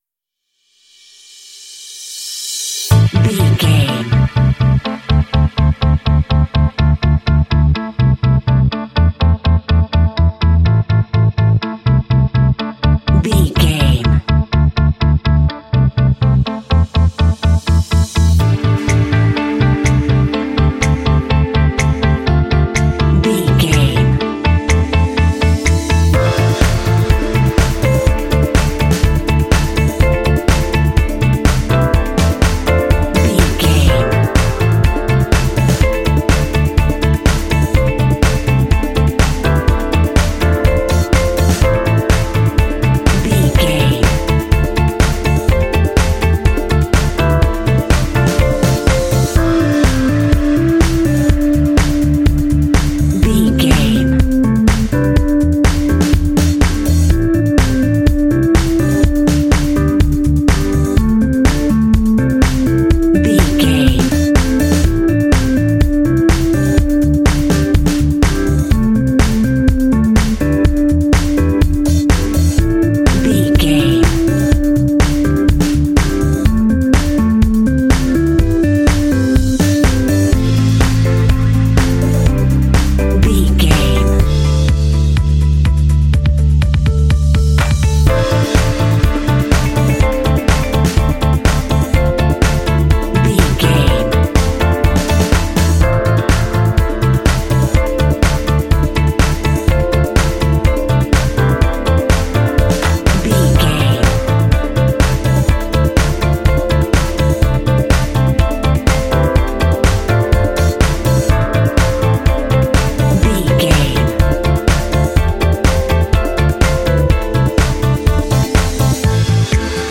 Uplifting
Aeolian/Minor
futuristic
groovy
high tech
electric guitar
percussion
drums
bass guitar
electric piano
synthesiser
strings
contemporary underscore
indie